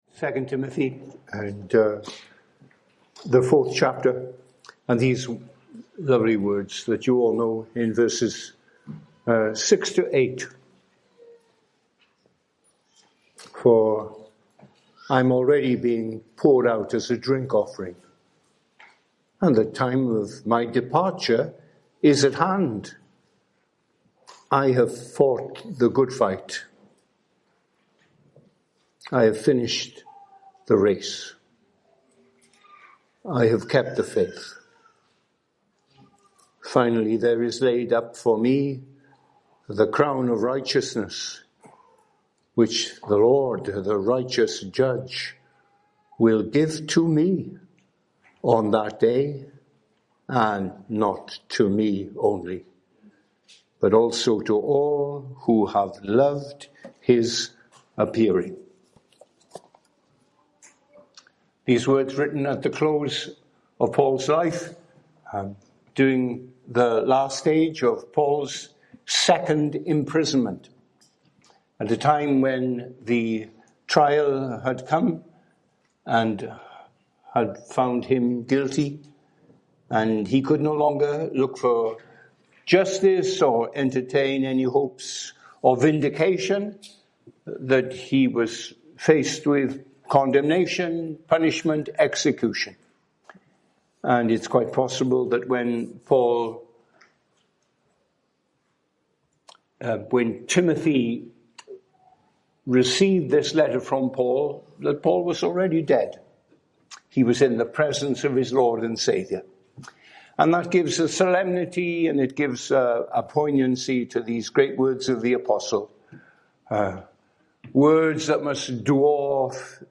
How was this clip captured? Fellowship Conference United Kingdom 2022 - Session 1 | Do you have an objective like Paul's, to keep the faith and to not abandon Christ?